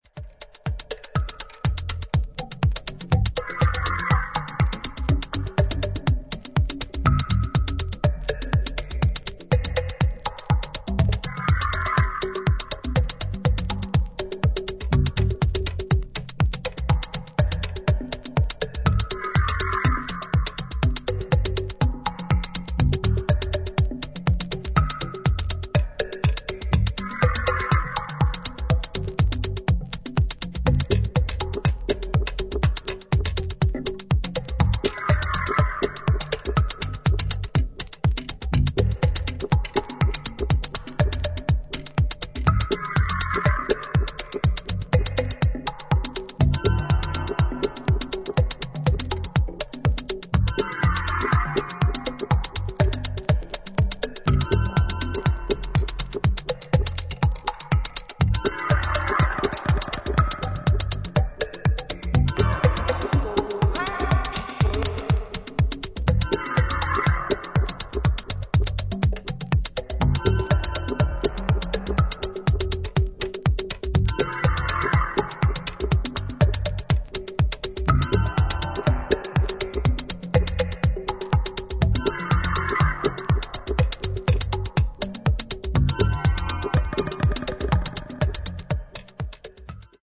パーカッシブなグルーヴを構成するチャントやパーカッションの音色に